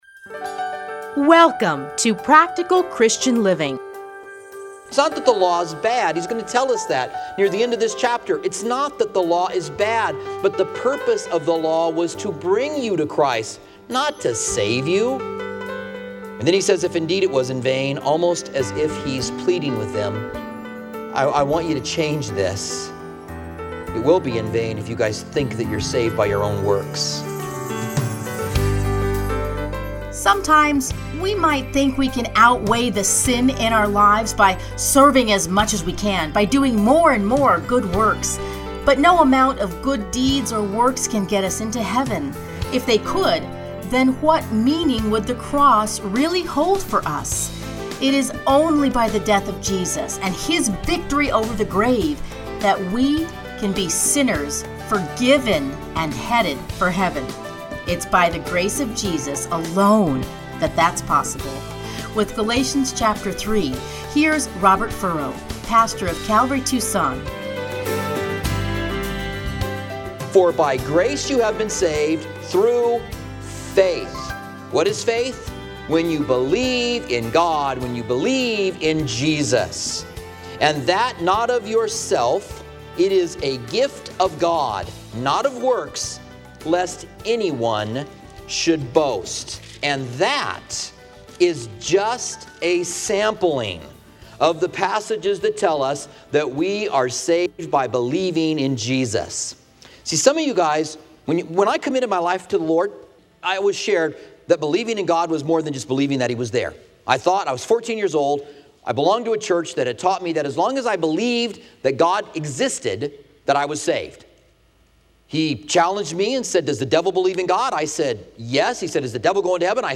Listen here to his commentary on Galatians.